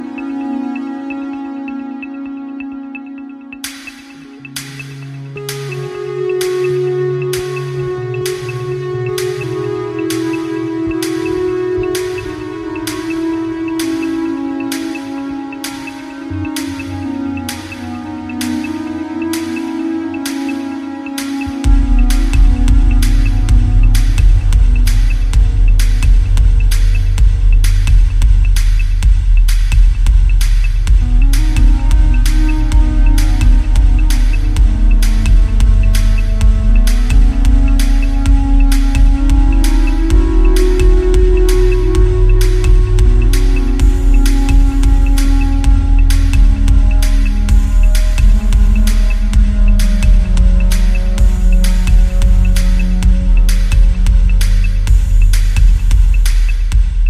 casting a dense yet permeable body of sound